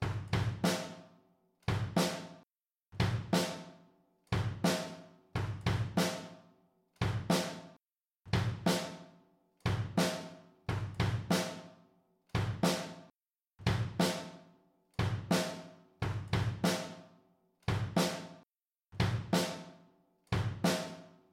描述：为Hiphop提供真正的鼓